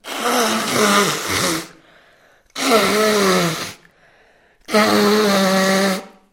Звуки сморкания
Высмаркивается в раковину туалета